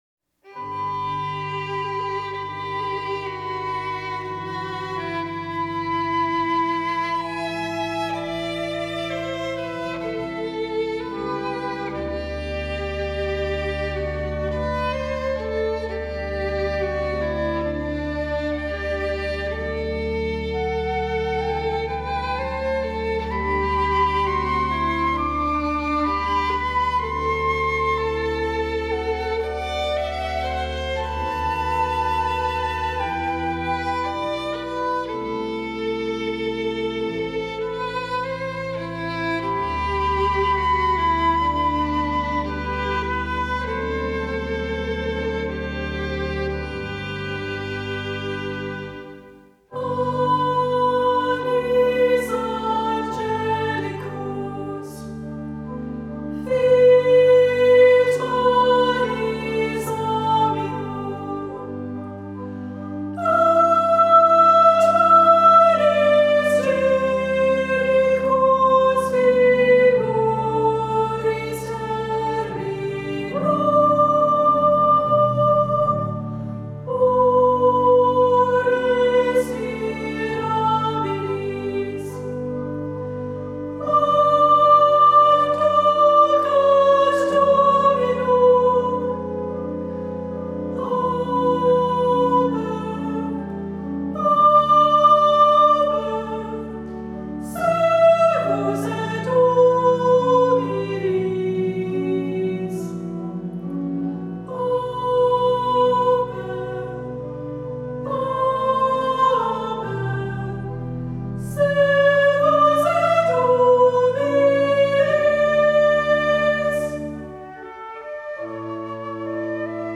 Voicing: 2-part Treble Choir